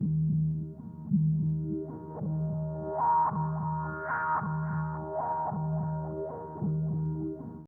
synth.wav